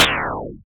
pow.ogg